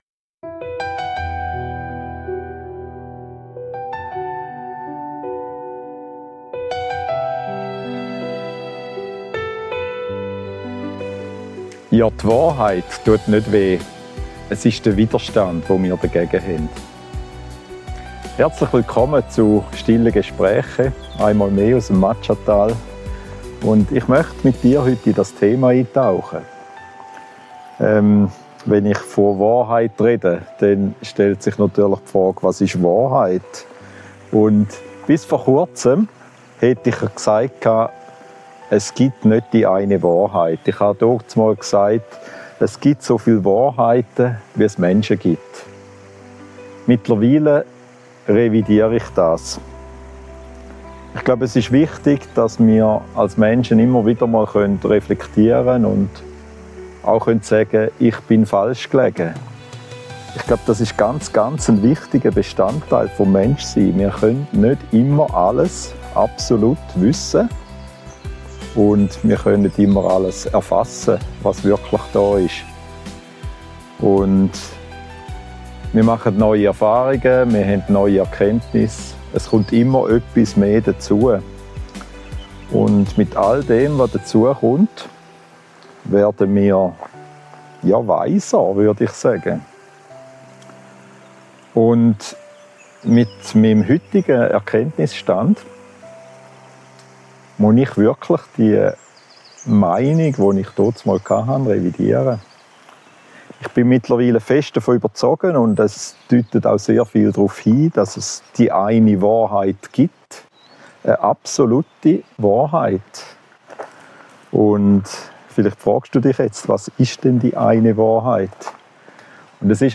Schweizerdeutsch gesprochen.